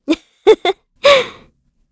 Hilda-Vox-Laugh_kr.wav